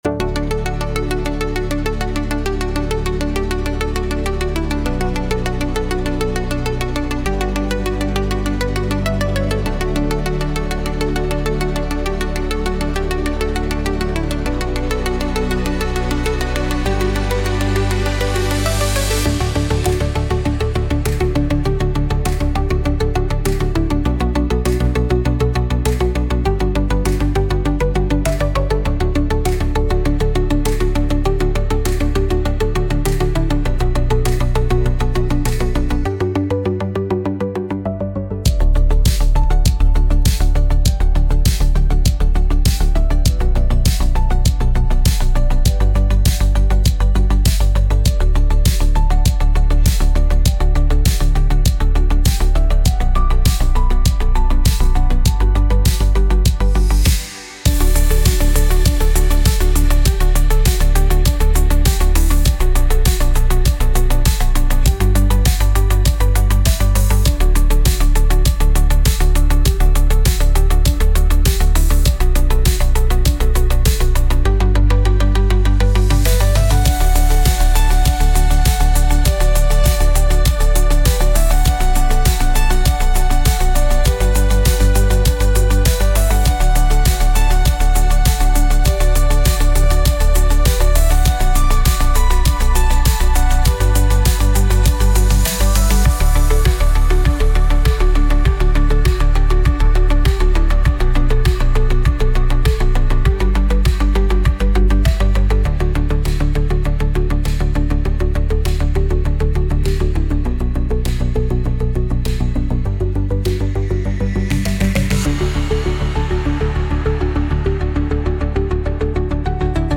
Instrumental - Real Liberty Media DOT xyz - 4. 00 mins